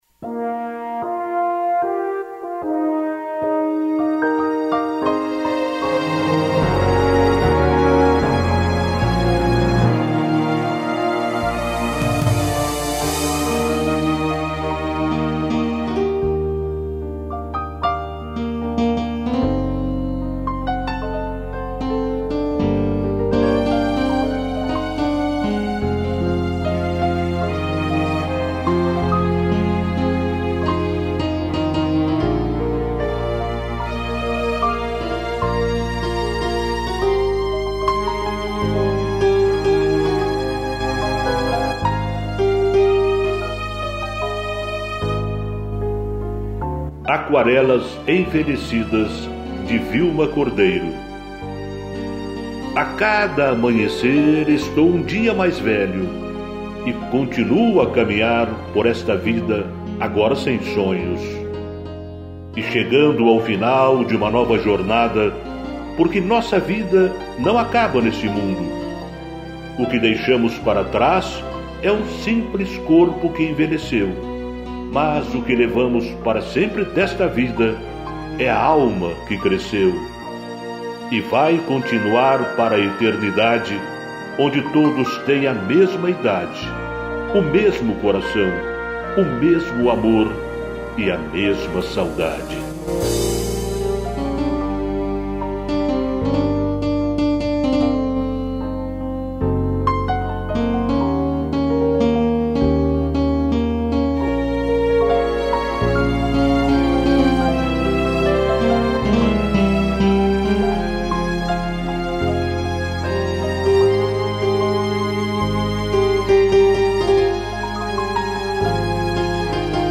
piano, violino e cello